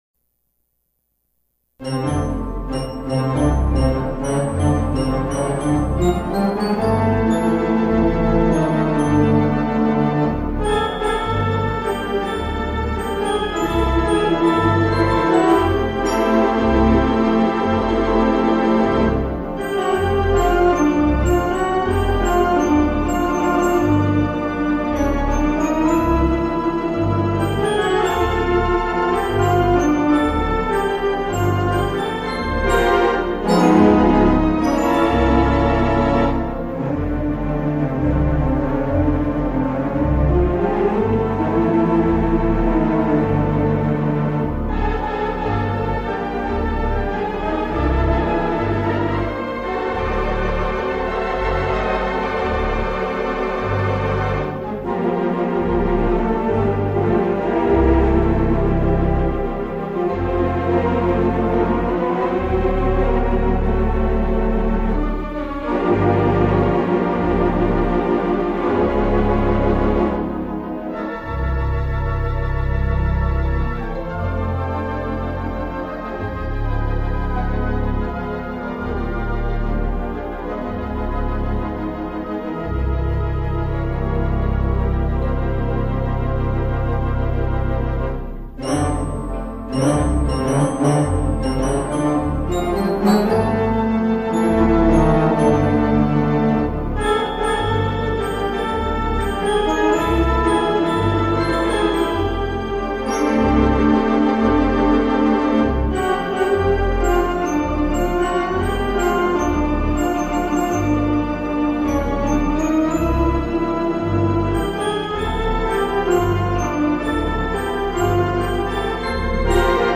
installed at Grace Baptist Church in Sarasota, Florida.
I am not super happy with the sound.